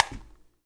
Clap26.wav